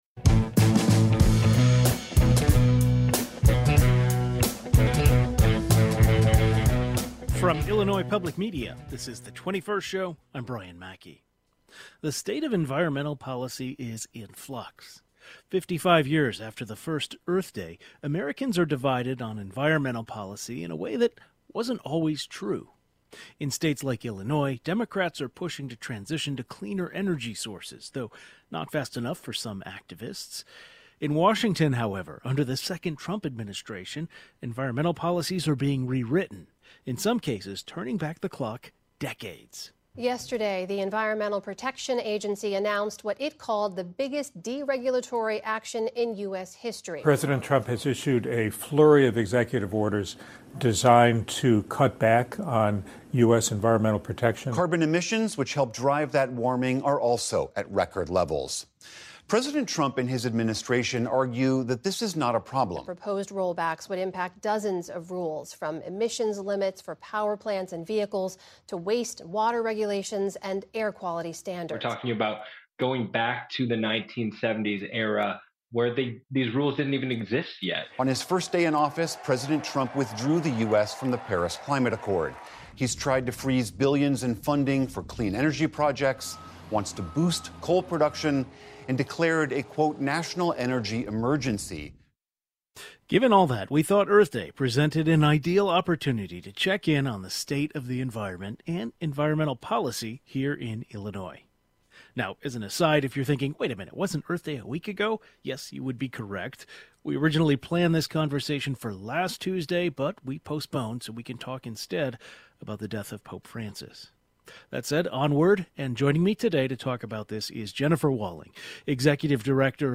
A reporter who covers climate issues as well as some environmental advocates join the conversation.